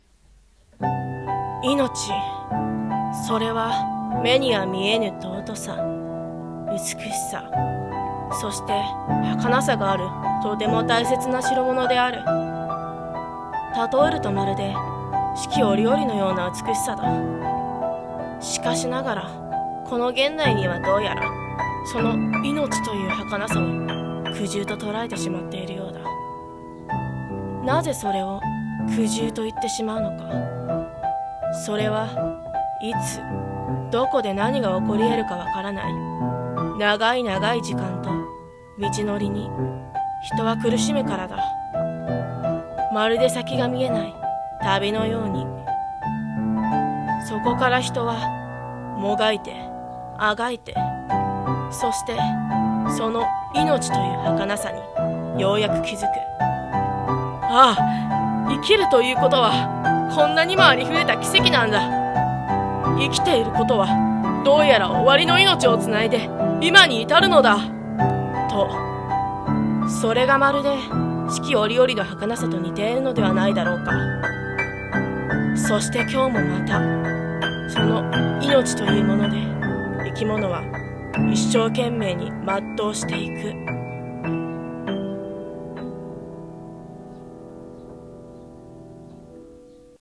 朗読者